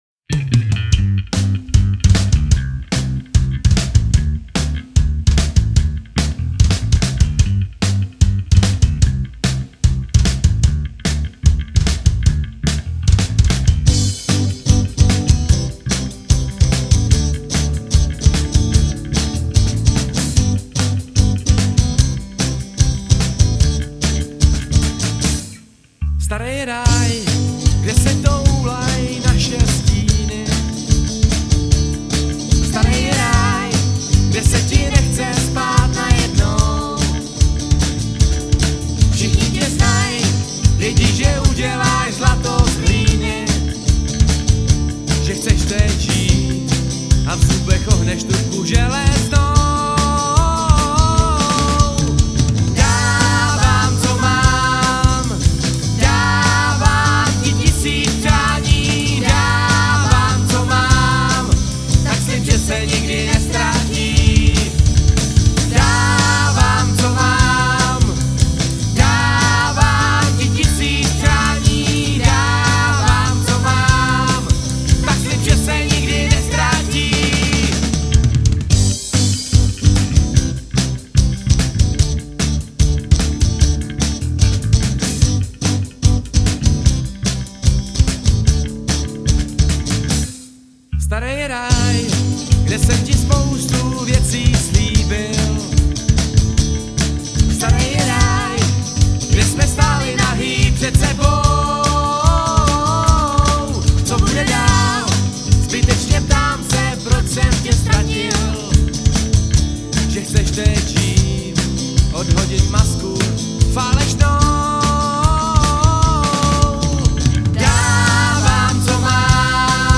rockovou kapelu